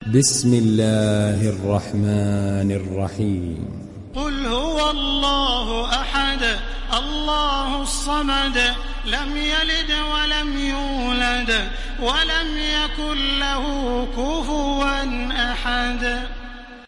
Download Surat Al Ikhlas Taraweeh Makkah 1430